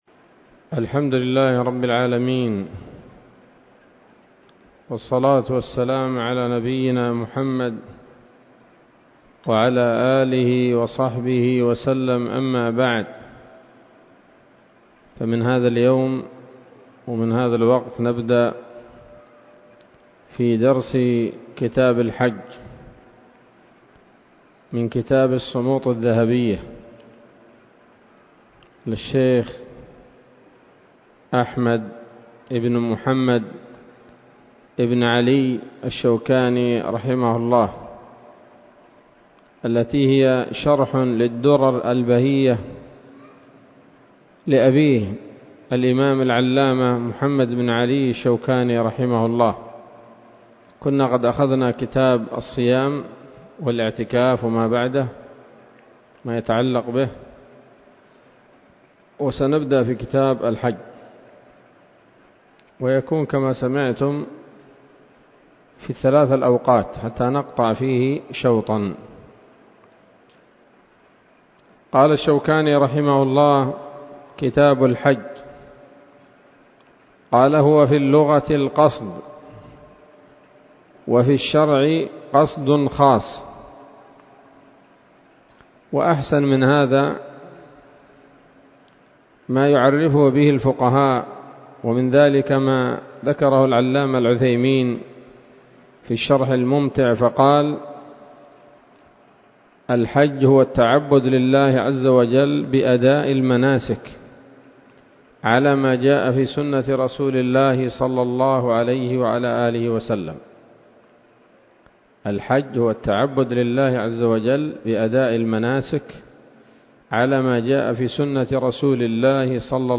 الدرس الأول من كتاب الحج من السموط الذهبية الحاوية للدرر البهية